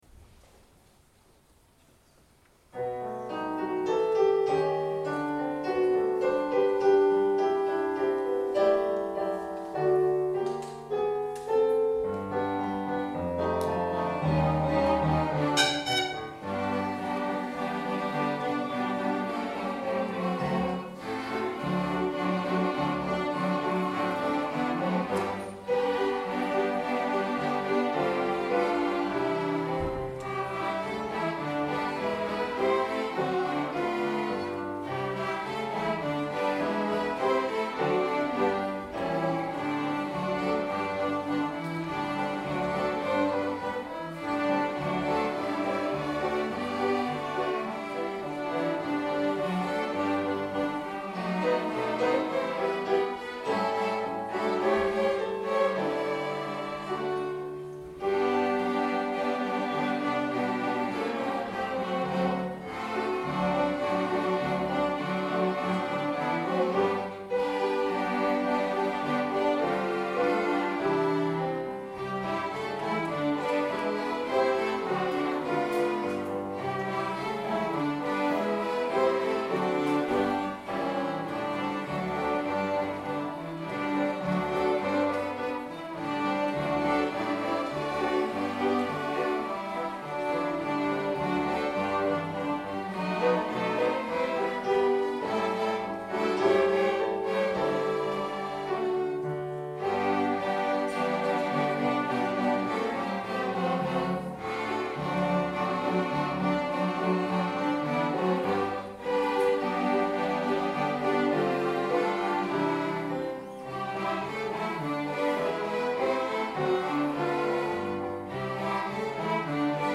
String Ensemble